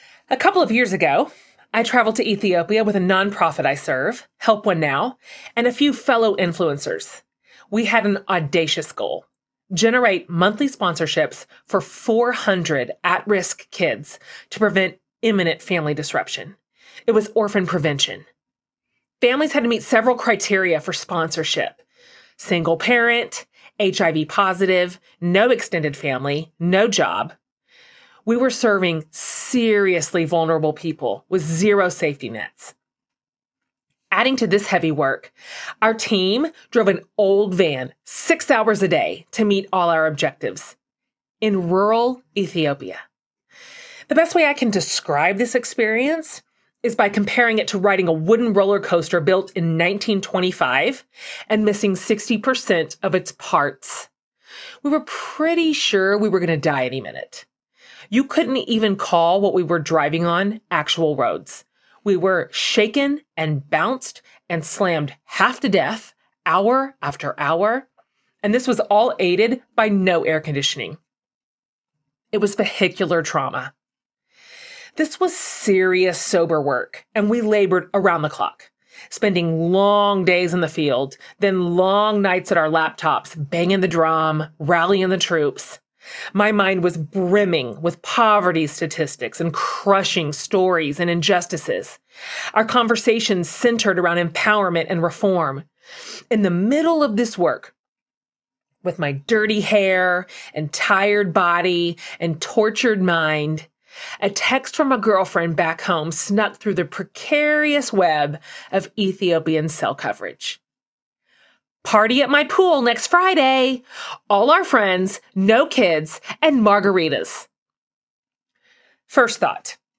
Of Mess and Moxie Audiobook